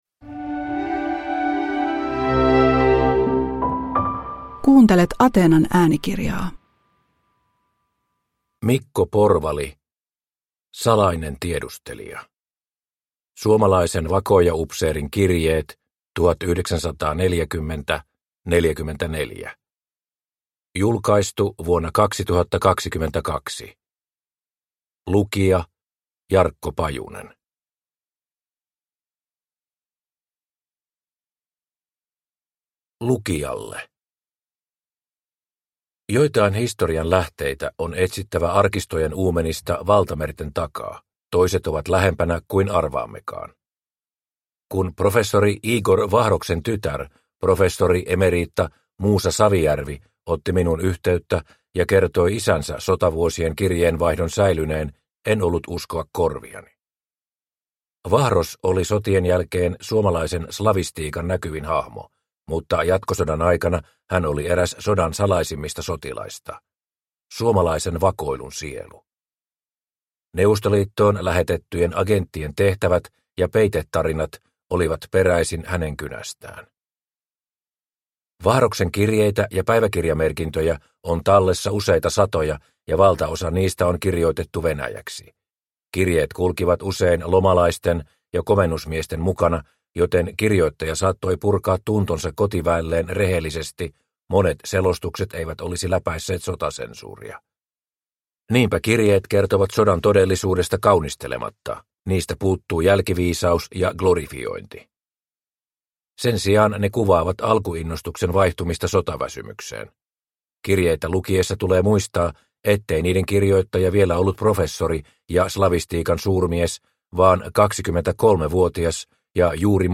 Salainen tiedustelija – Ljudbok – Laddas ner